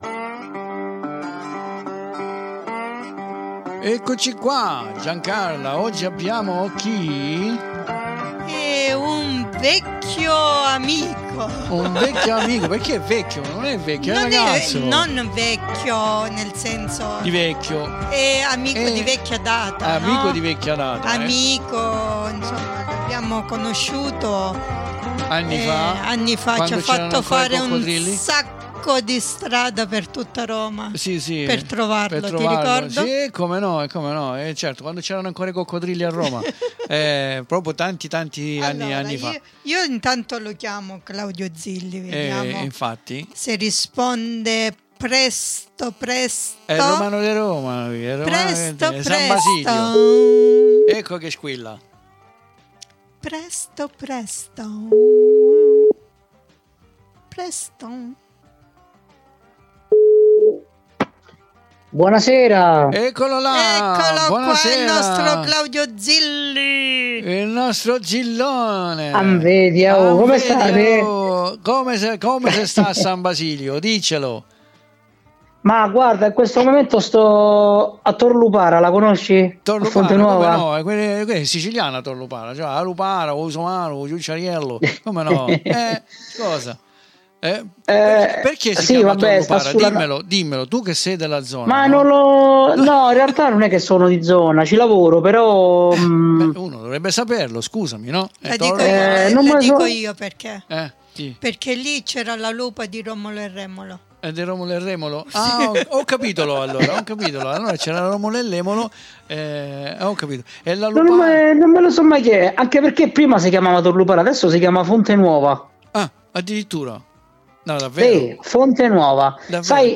ED ECCOLO DI NUOVO QUI A CHIACCHERARE CON NOI!